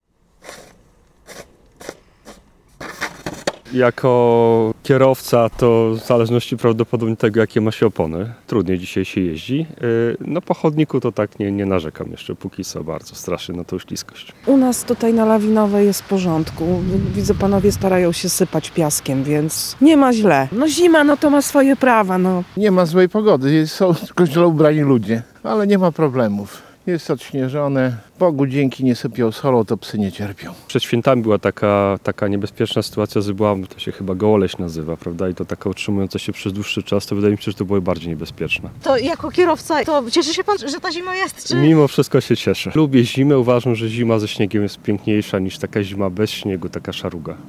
Piesi i kierowcy nie narzekają na warunki drogowe: W województwie lubelskim do godziny 8.00 rano obowiązuje pierwszy stopień ostrzeżenia przed oblodzeniem.